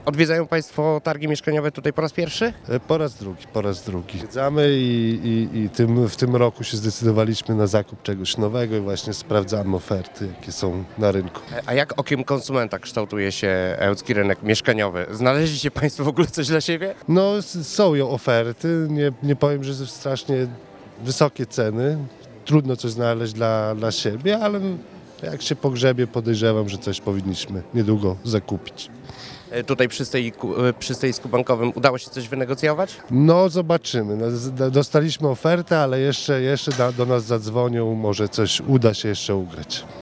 Odwiedzający Targi Mieszkaniowe i Wyposażenia Wnętrz, przyznają, że oferta tylu firm w jednym miejscu to duże ułatwienie w wyborze mieszkania i jego wyposażenia.
SONDA-.mp3